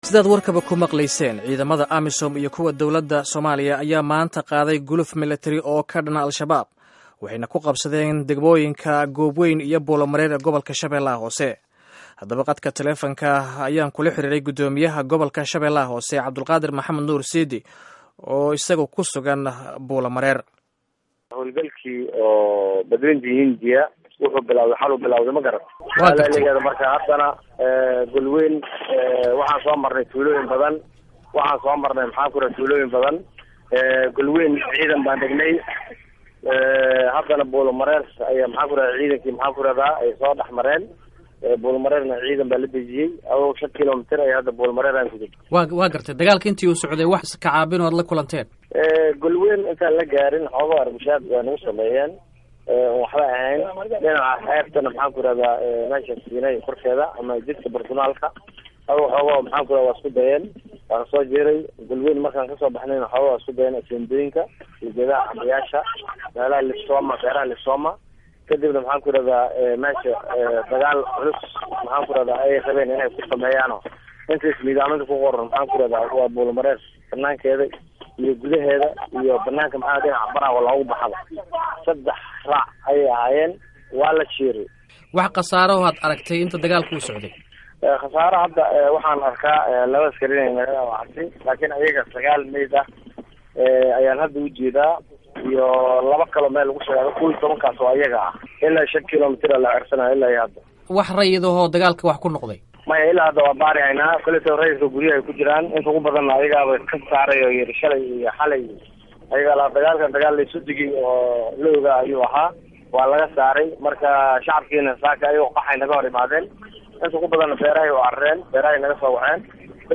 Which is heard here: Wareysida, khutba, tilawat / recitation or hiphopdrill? Wareysida